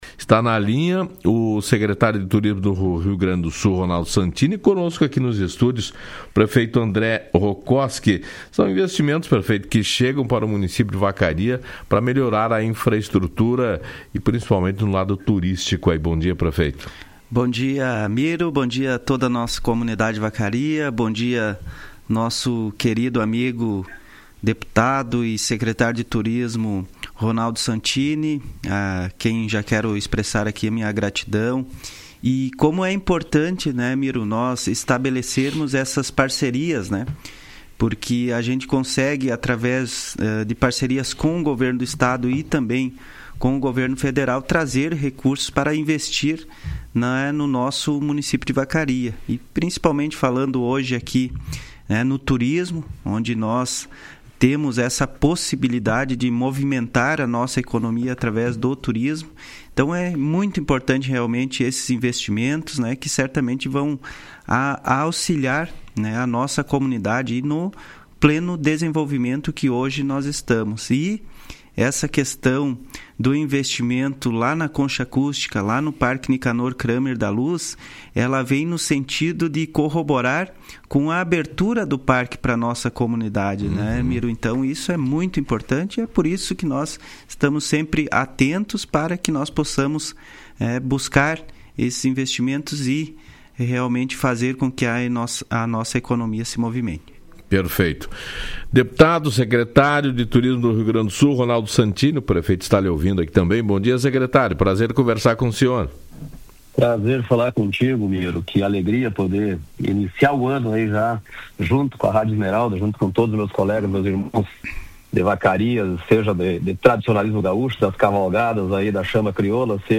Em entrevista à Rádio Esmeralda na manhã desta quarta-feira, o secretário estadual de turismo, Ronaldo Santini, disse que esta obra irá contribuir para a melhoria da estrutura do parque Nicanor Kramer da Luz, além de representar uma economia para a realização de eventos futuros, já que a estrutura estará disponível para uso de forma permanente.
ENTREVISTA-SANTINI-0701.mp3